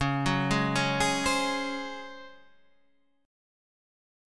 DbM7sus4 chord